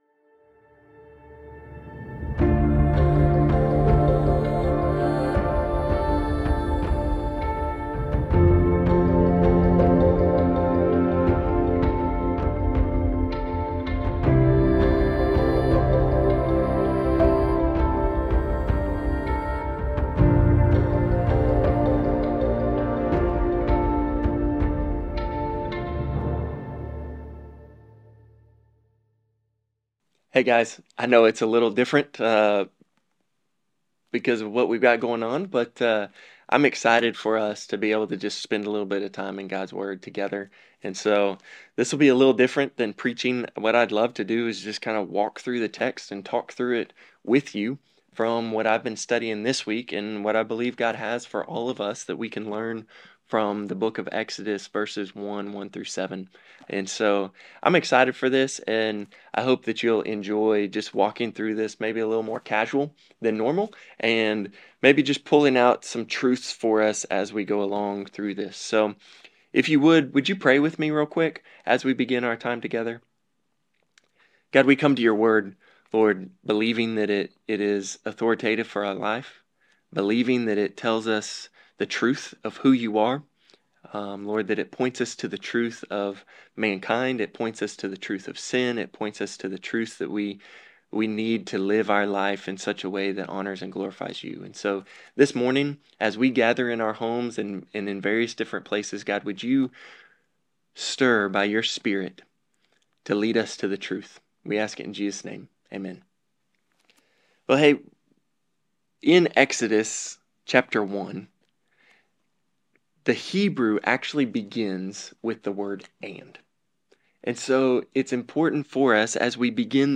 Norris Ferry Sermons Jan. 25, 2026 -- Exodus 1:1-7 Jan 28 2026 | 00:15:43 1x 00:00 / 00:15:43 Subscribe Share Spotify RSS Feed Share Link Embed